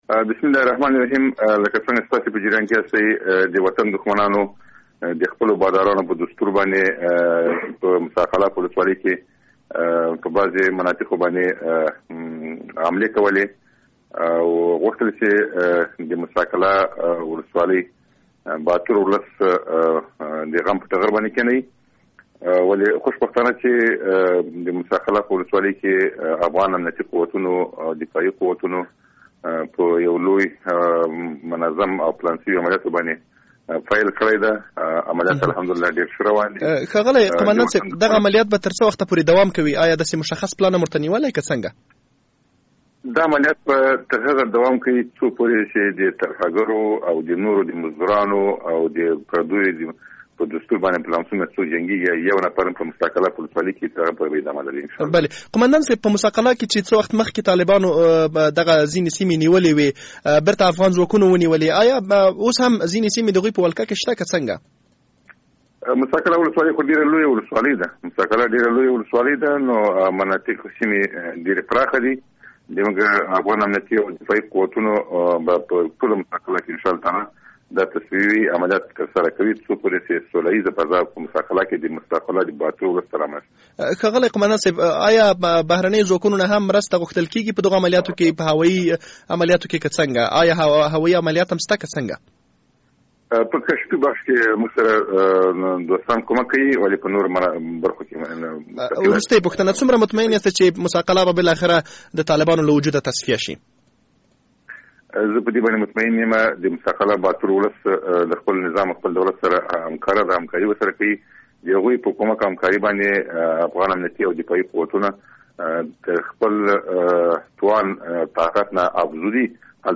مرکه
د هلمند له امنیه قومندان سره مرکه